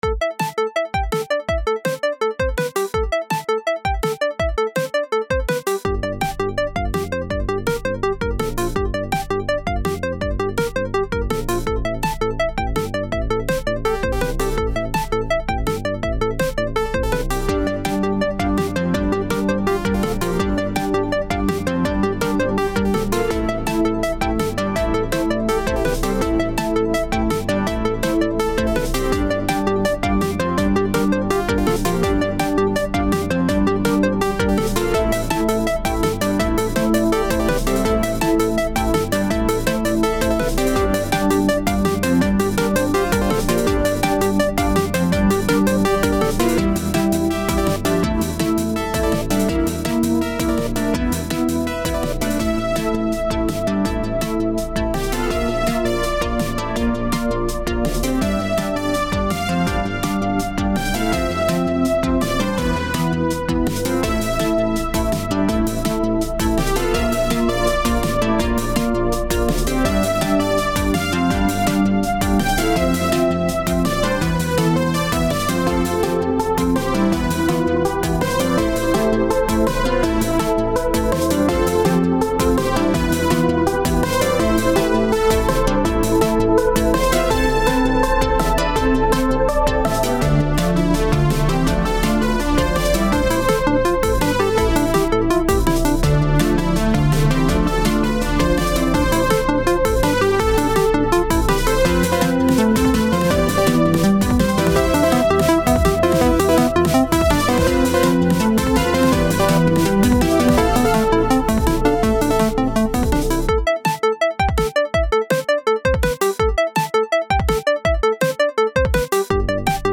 sort of by-the-numbers electronic remix